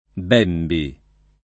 [ b $ mbi ]